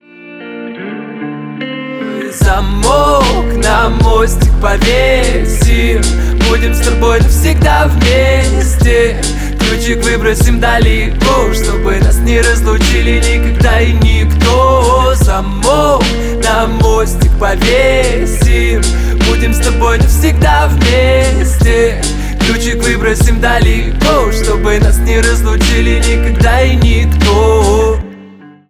гитара
поп
рэп